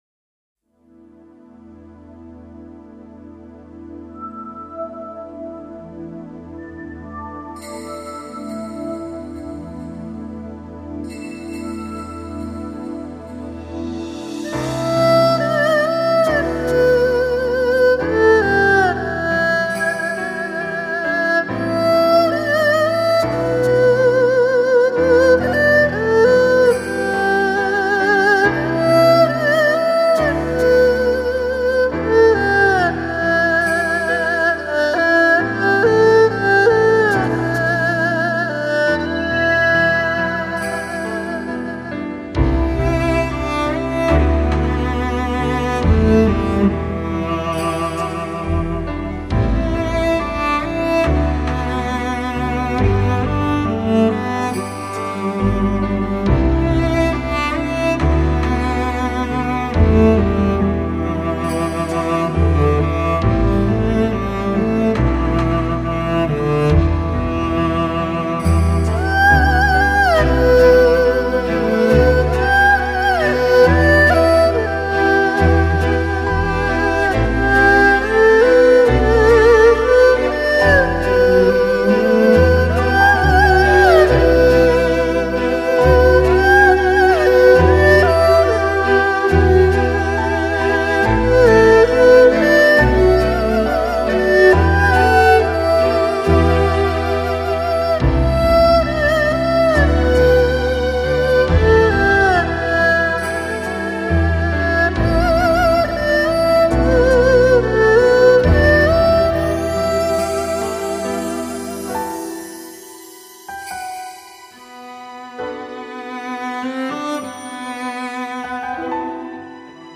编曲、ピアノ、シンセサイザープログラミング
チェロ